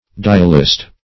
Dialist \Di"al*ist\, n. A maker of dials; one skilled in dialing.